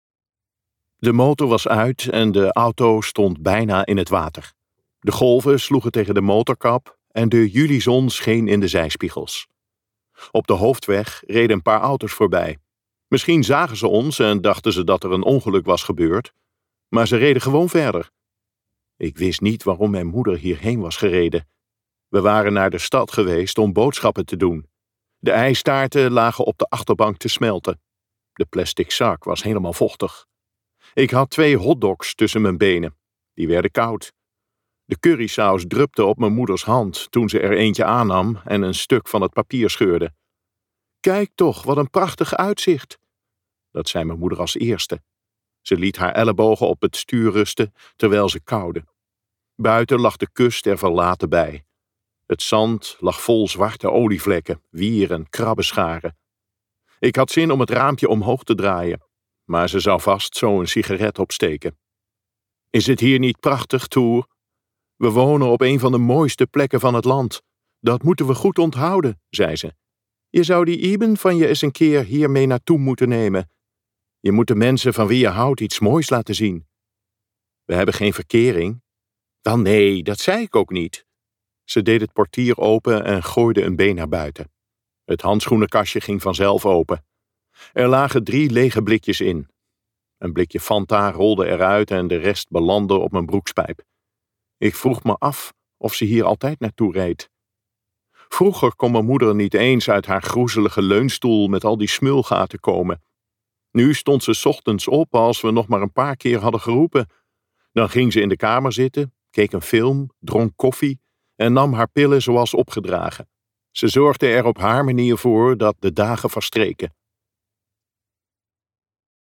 Op een dag lachen we erom luisterboek | Ambo|Anthos Uitgevers